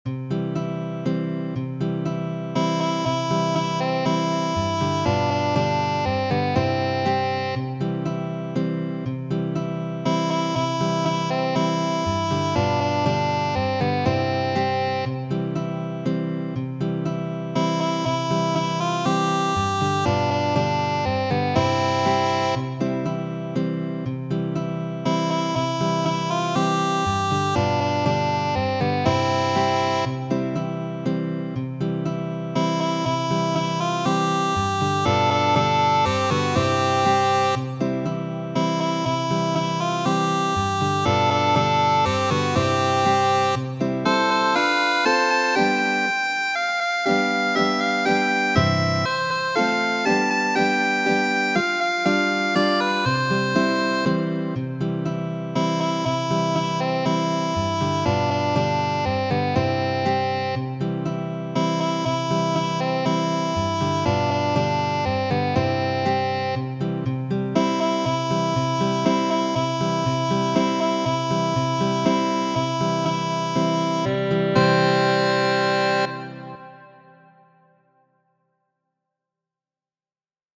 Promeno, en gitara versio.